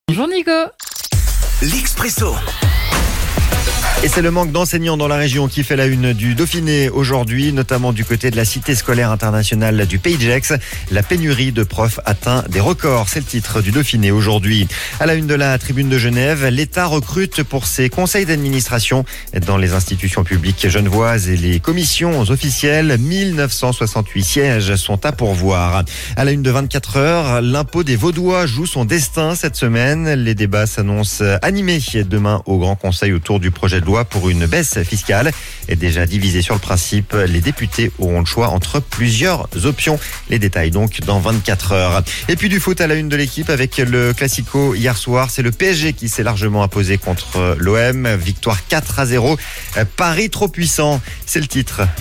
La revue de presse de la Radio Plus